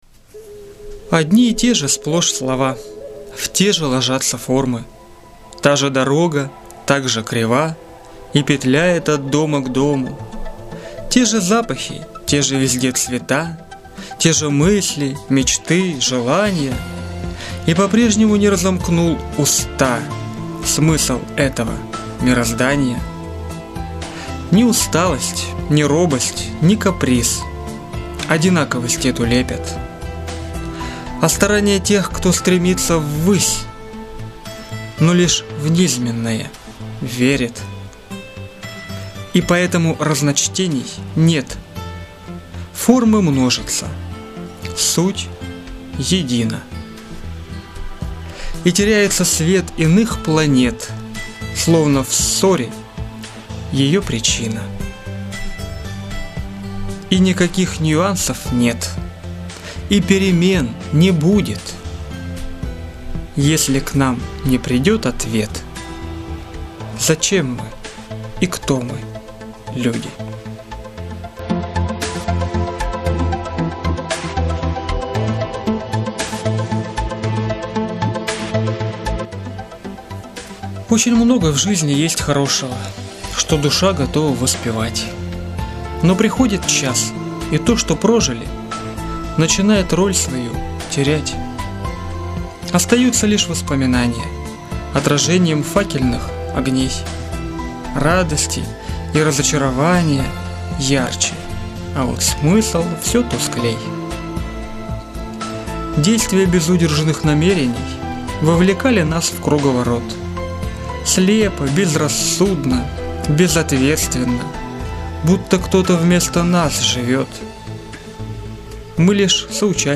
Лирическая: